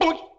Vocal boink sound.
Vocal_Boink.oga.mp3